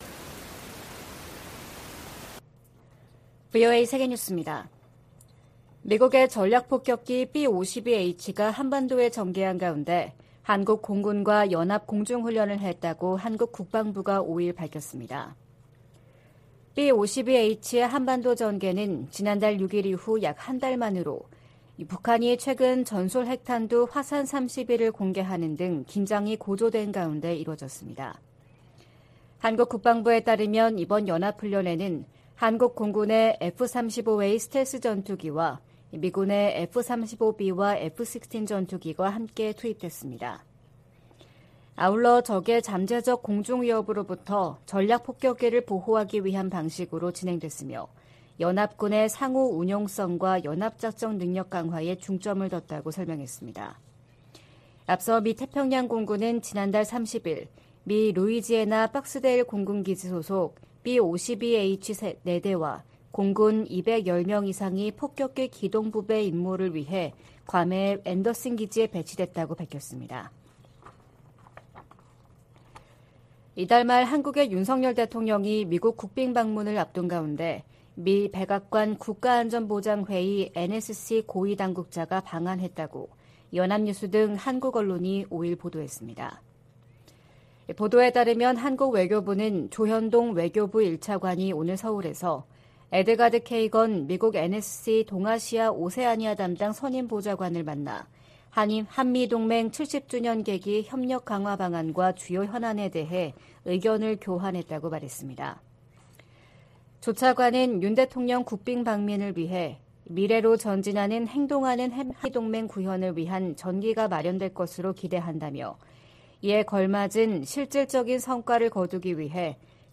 VOA 한국어 '출발 뉴스 쇼', 2023년 4월 6일 방송입니다. 유엔 인권이사회가 북한의 조직적 인권 침해를 규탄하고 개선을 촉구하는 내용의 북한인권결의안을 채택했습니다. 한국 국방부는 5일 미 공군 B-52H 전략폭격기가 한반도 상공에서 한국 공군 F-35A 전투기 등과 연합공중훈련을 실시했다고 밝혔습니다.